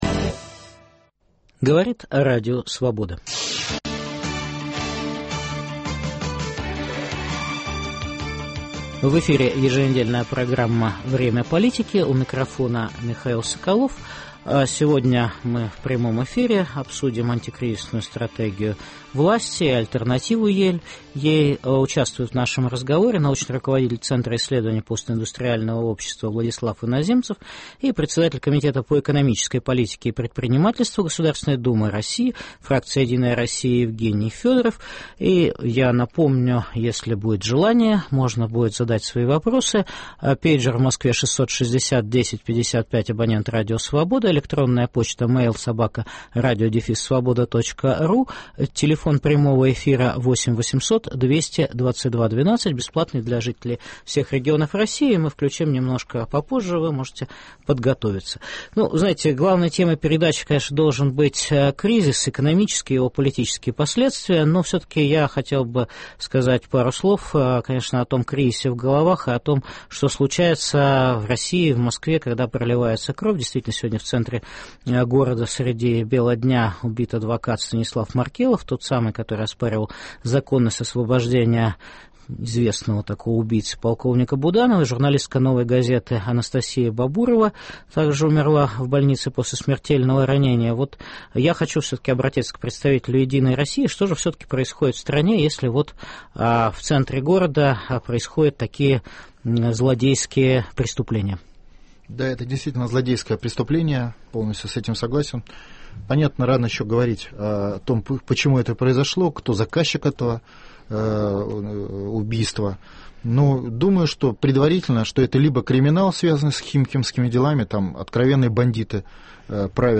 В прямом эфире обсуждаем антикризисную стратегию правительства и альтернативу ей. Участвуют научный руководитель Центра исследования постиндустриального общества Владислав Иноземцев и председатель комитета по экономической политике и предпринимательству Государственной Думы России (фракция «Единая Россия») Евгений Федоров.